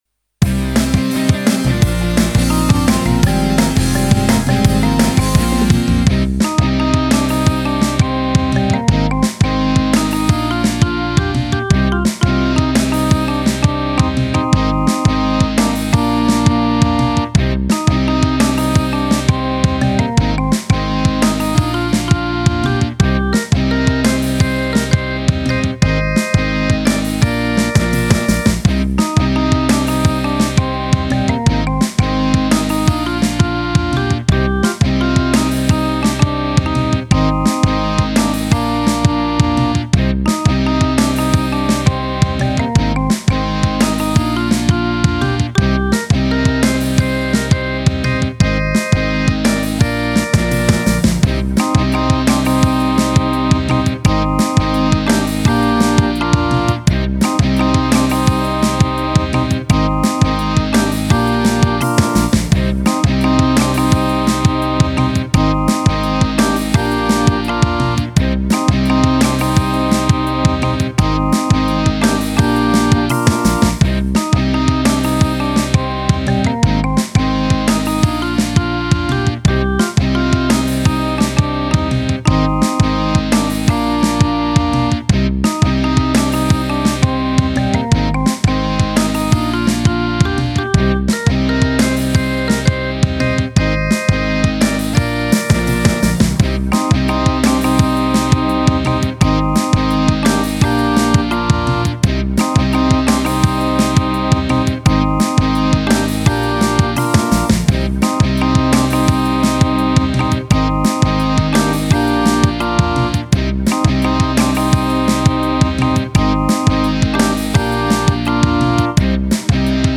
8 Beat
Ik voeg steeds nieuwe ritmedemo's bovenaan toe, scroll dus naar beneden om alles te zien.
Roland E X 10 8 Beat 004 Mersy Rock Mp 3